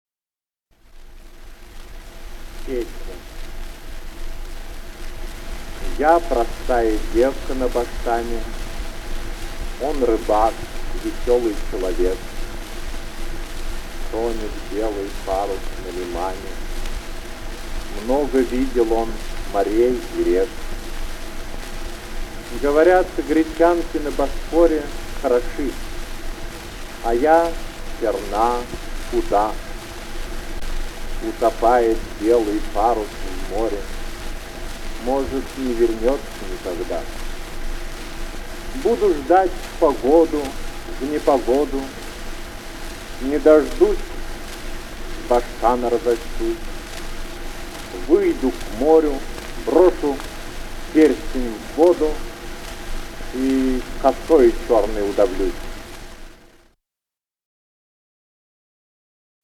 (Голоса авторов) Иван Бунин